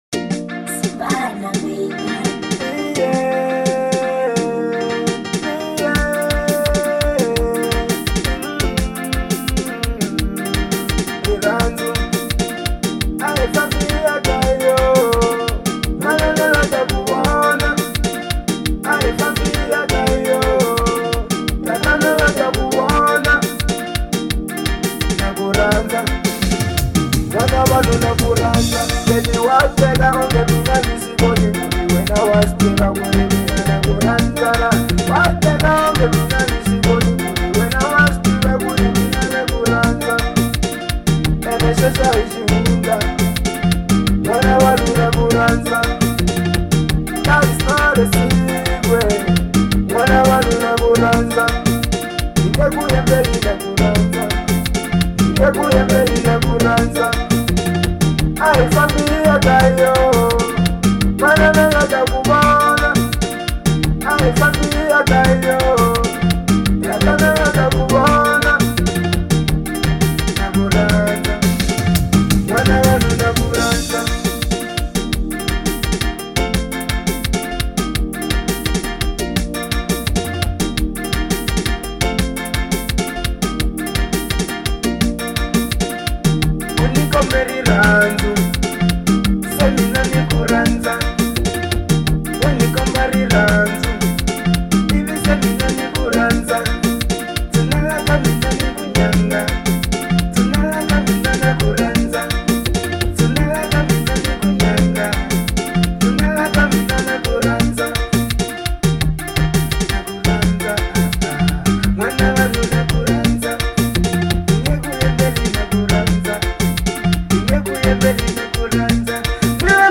06:15 Genre : Xitsonga Size